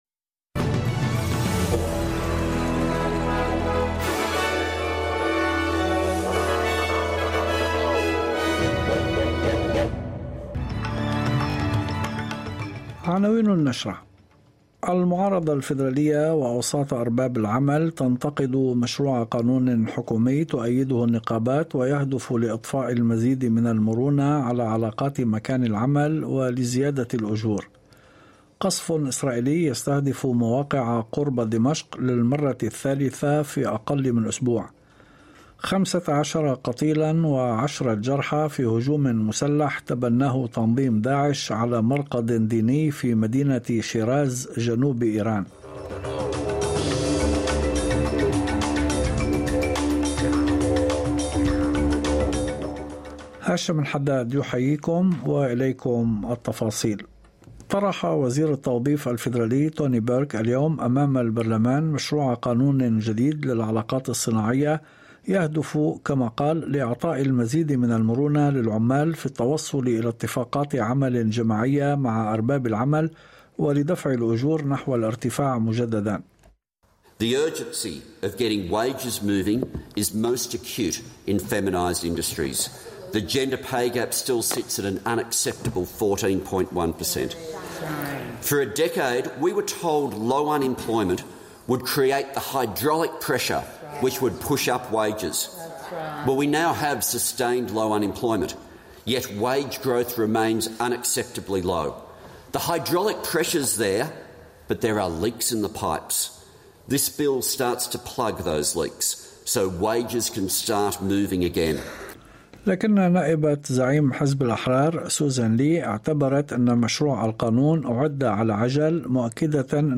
نشرة أخبار المساء 27/10/2022